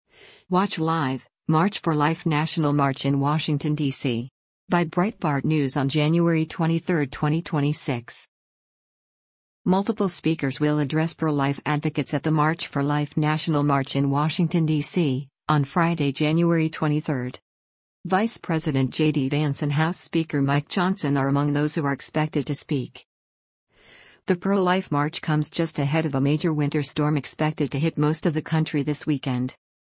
Multiple speakers will address pro-life advocates at the March for Life national march in Washington, DC, on Friday, January 23.
Vice President JD Vance and House Speaker Mike Johnson are among those who are expected to speak.